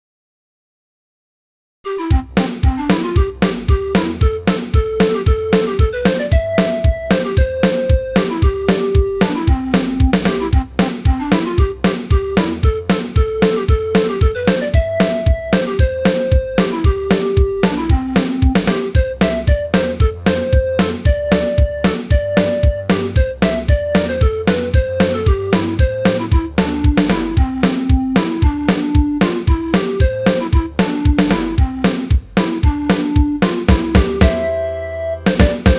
Midi File, Lyrics and Information to Dixie